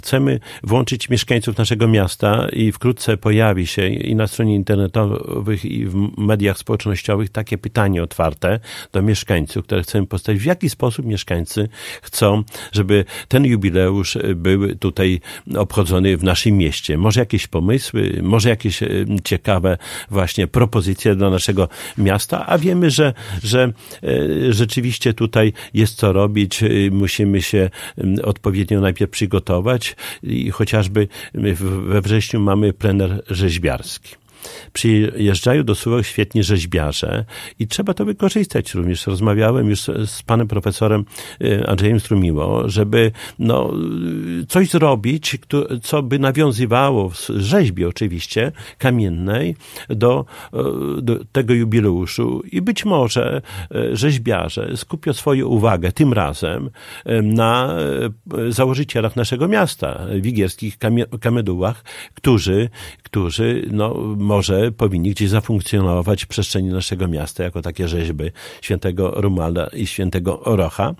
O szczegółach mówił w piątek (11.08) w Radiu 5 Czesław Renkiewicz, prezydent Suwałk.